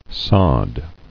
[sod]